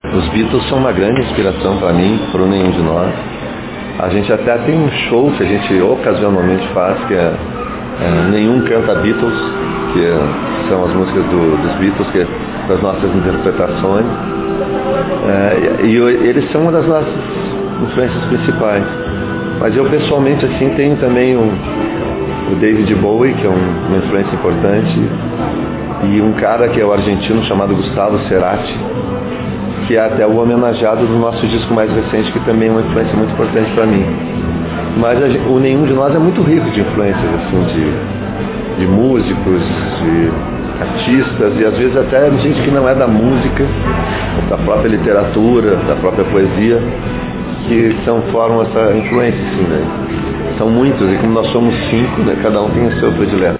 O artista aproveitou para divulgar seus livros e concedeu uma coletiva de imprensa e sessão de autógrafos.